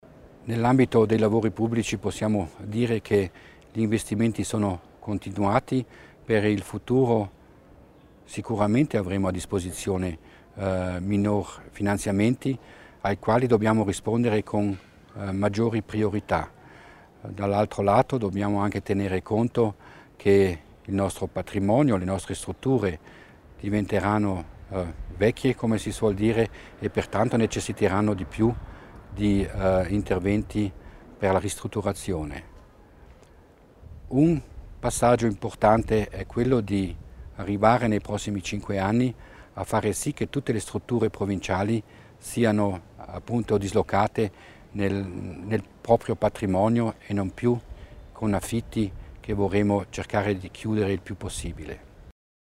Dai grandi progetti per le opere pubbliche alla gestione della rete stradale, dalla riduzione della burocrazia alla banda larga come infrastruttura chiave per la futura competitività del territorio. Questi alcuni degli argomenti trattati oggi (5 agosto) a Ortisei dall'assessore provinciale Florian Mussner durante il colloquio di fine legislatura con i rappresentanti dei media.